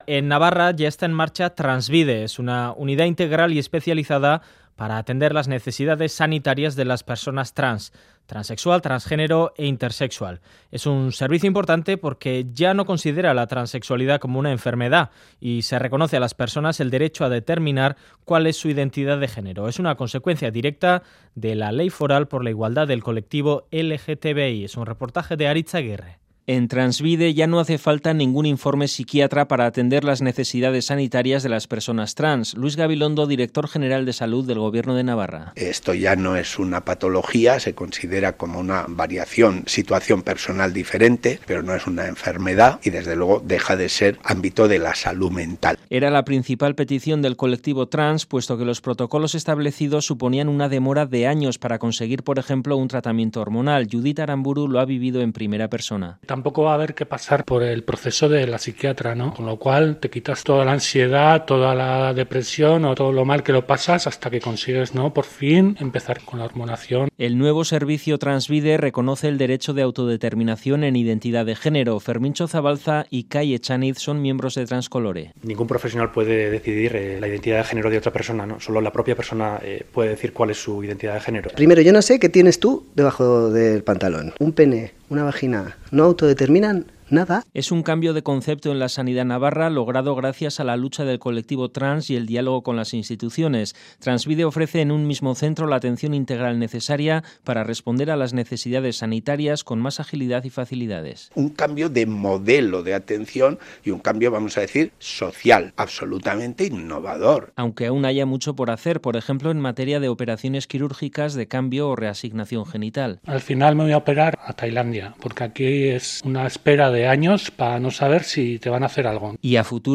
Radio Euskadi REPORTAJES Transbide, un servicio público fruto de la lucha del colectivo LGTBI+ Última actualización: 06/03/2018 09:50 (UTC+1) El Departamento de Salud del Gobierno de Navarra ha creado Transbide, una unidad de atención integral y especializada para las personas transexuales.